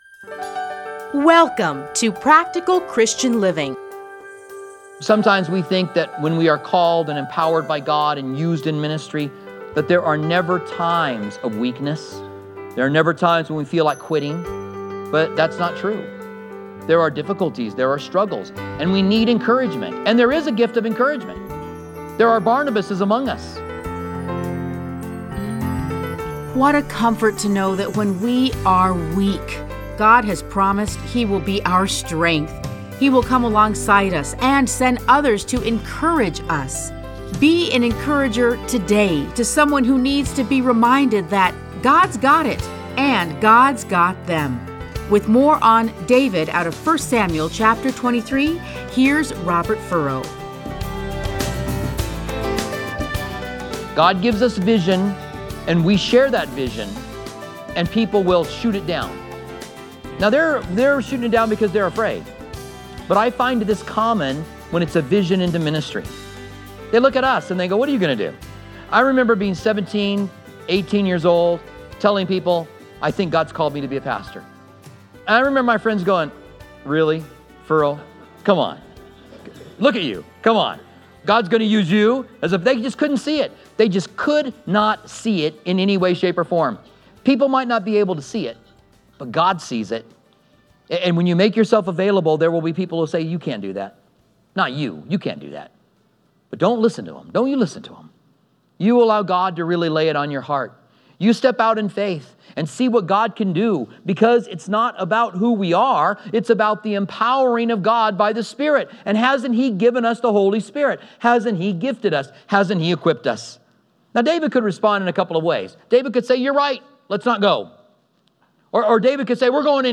Listen to a teaching from 1 Samuel 23:1-29.